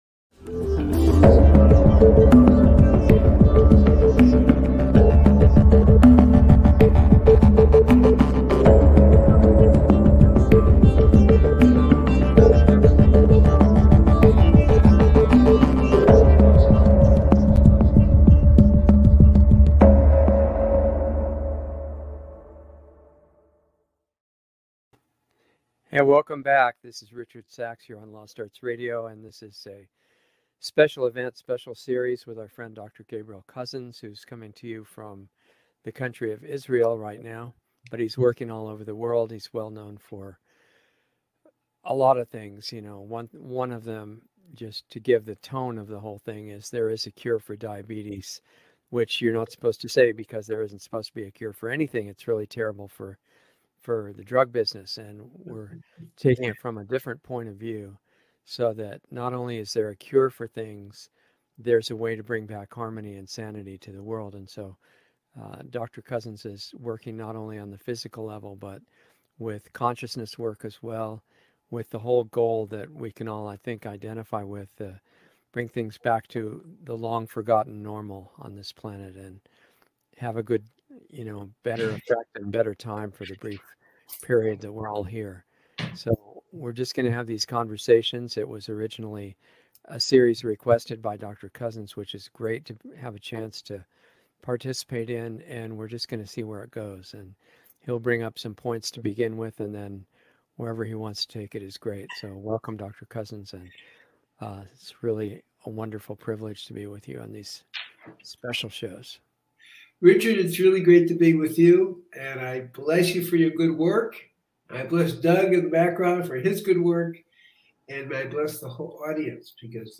Lost Arts Radio Live - Conversations